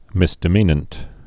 (mĭsdĭ-mēnənt)